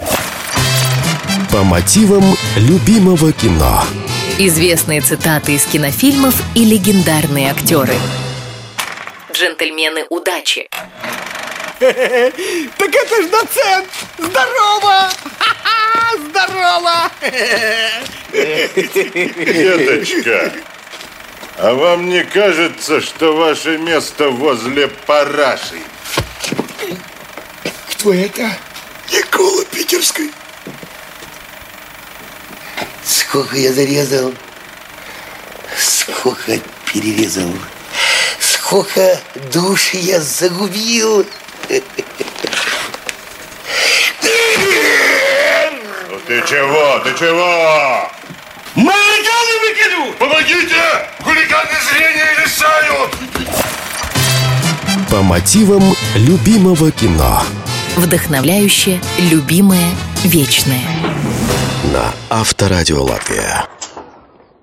В этой программе вы услышите знаменитые цитаты из кинофильмов, озвученные голосами легендарных актеров.